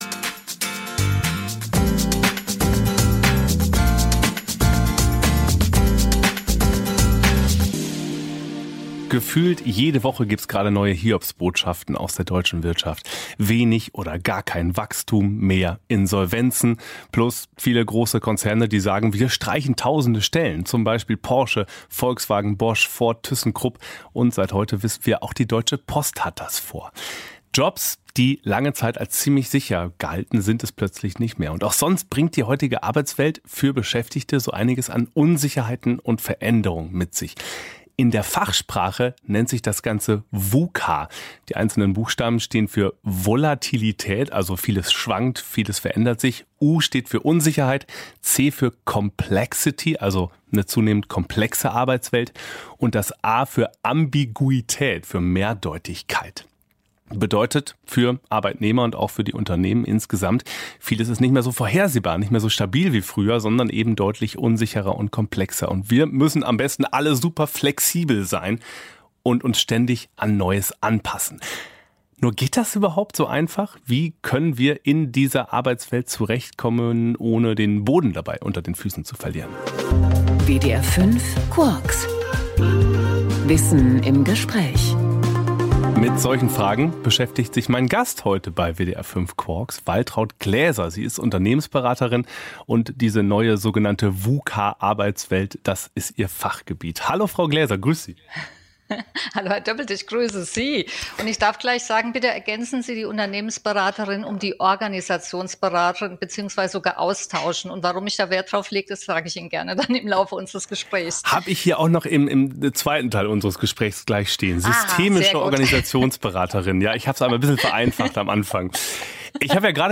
WDR Interview